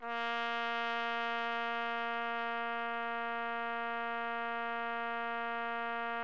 TRUMPET    5.wav